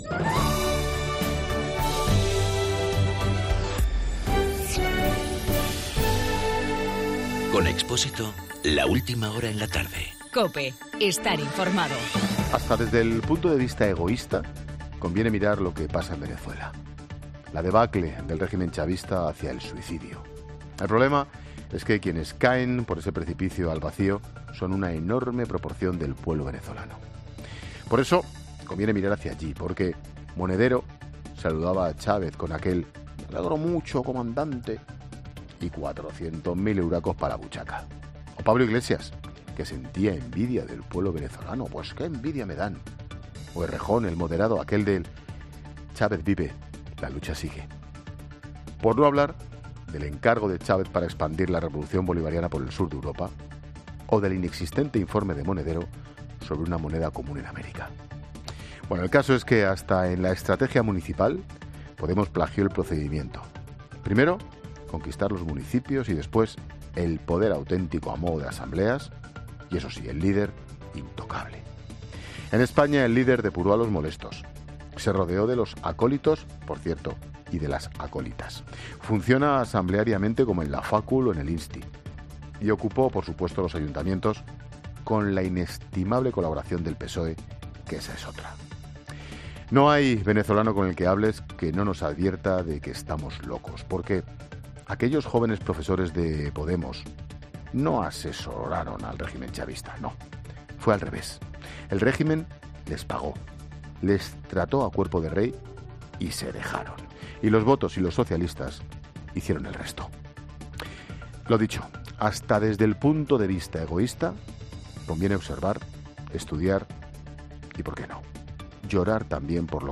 El comentario de Ángel Expósito de camino a Centro América.